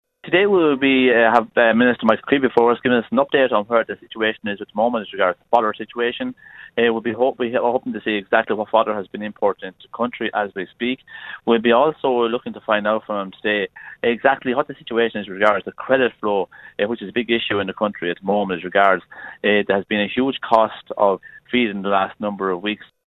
Committee Chair Fine Gael Deputy Pat Deering says they want a detailed update on the crisis: